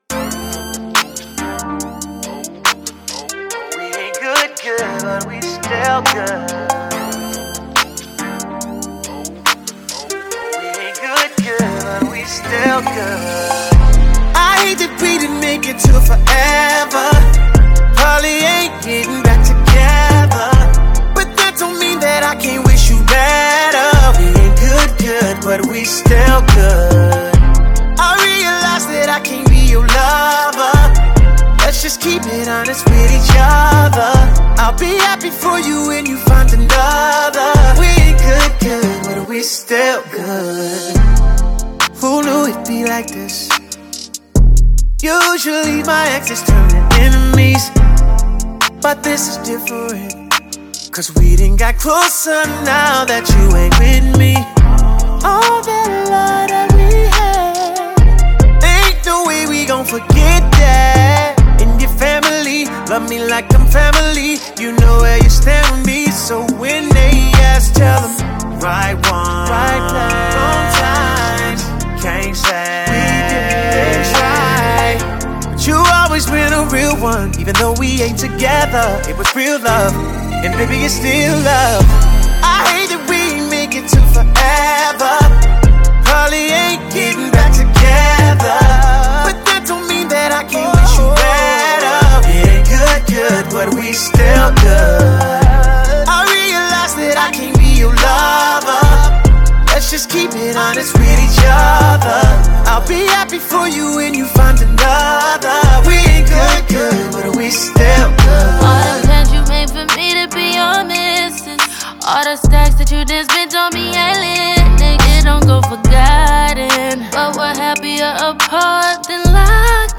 catchy new single